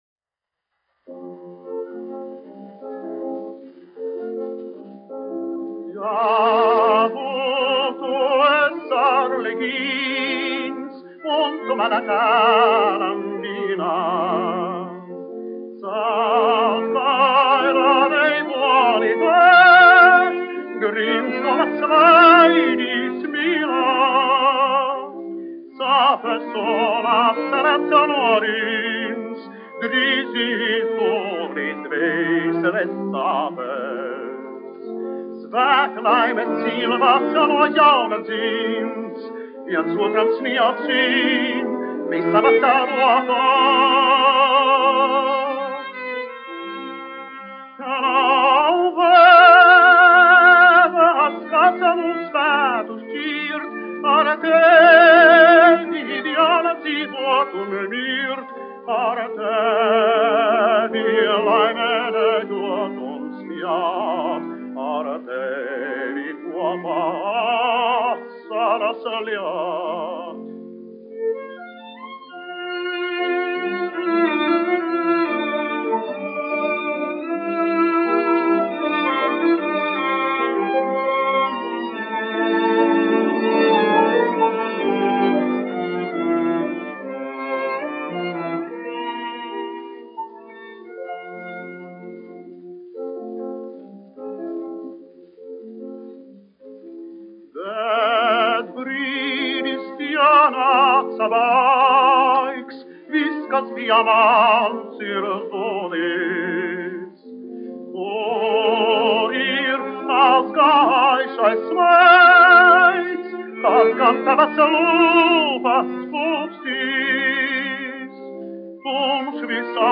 1 skpl. : analogs, 78 apgr/min, mono ; 25 cm
Baleti--Fragmenti, aranžēti
Skaņuplate